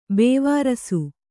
♪ bēvārasu